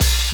Index of /90_sSampleCDs/Best Service Dance Mega Drums/HIHAT HIP 1B